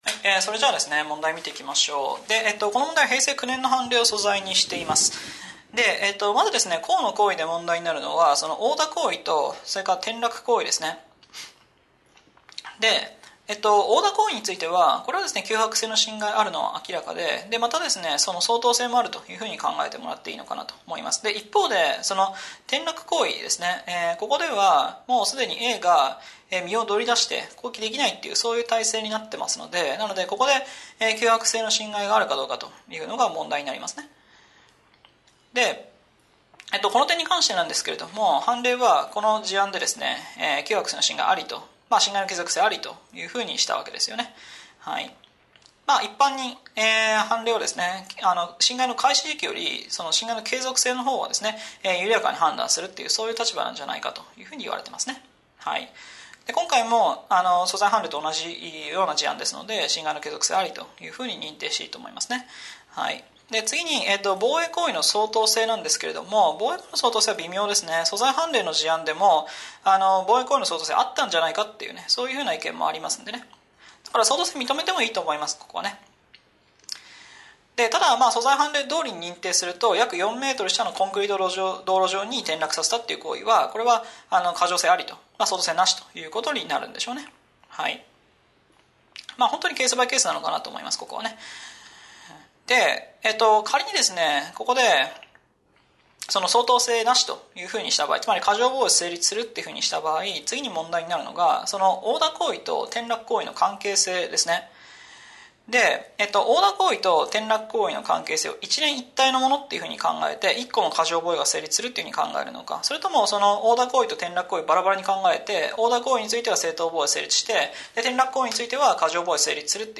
解説音声